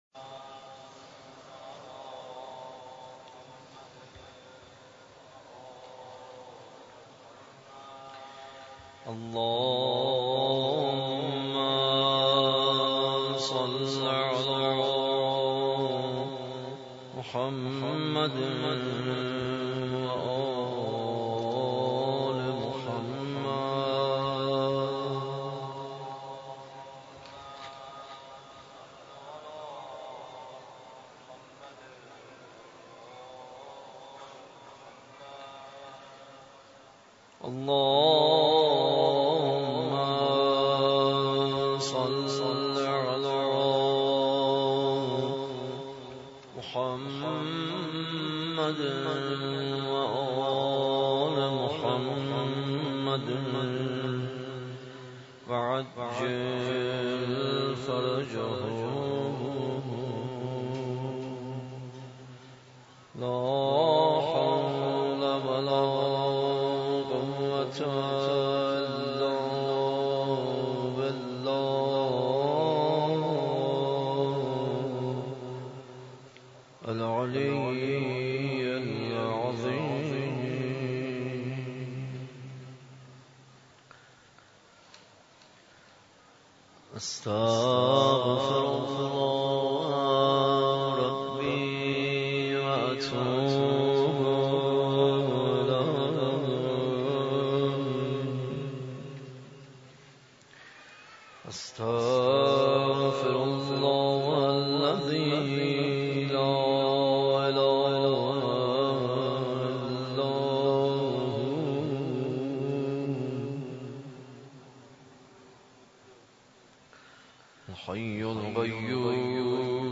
دعای جوشن صغیر